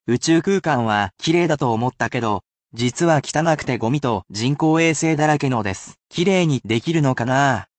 [casual speech]